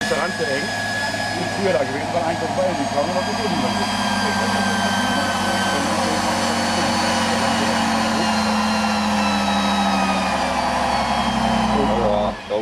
25.05.2006 bis 27.05.2006 - Off-Road-Treffen auf dem Buchenhof in Reddereitz